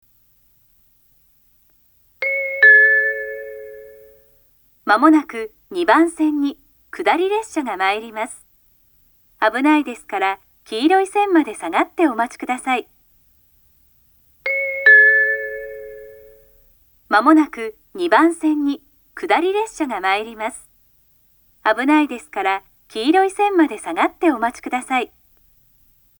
ホーム上の大きなTOA製およびPanasonic製ラッパから流れます。
接近放送
女性による接近放送です。方面別の案内はなく、通過列車も含めてこの放送です。